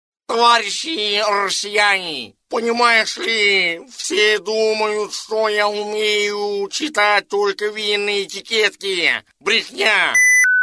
Пародия на голос Ельцина, Бориса Николаевича, запись пародиста он-лайн
Характеристика: Пародист